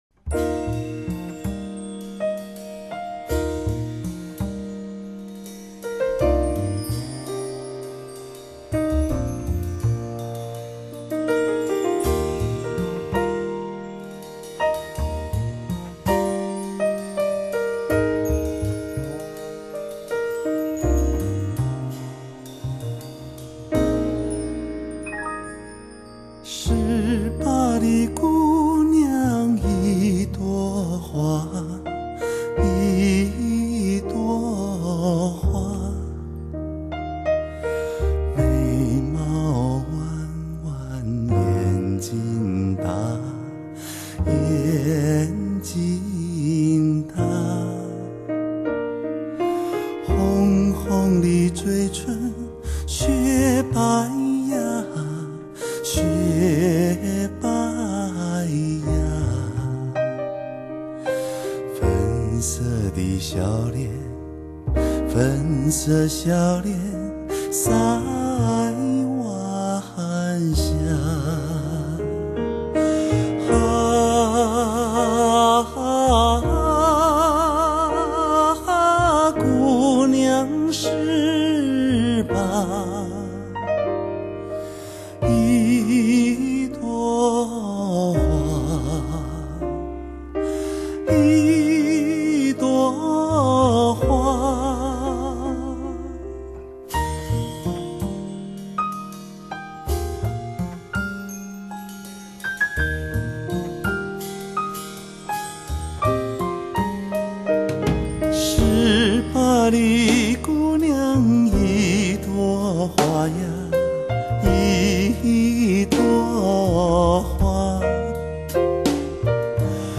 超水准的录音效果，音色甜美自然，堂音丰富，而且乐器与人声的质 感和比例非常出色。
风格流派：Jazz/Pop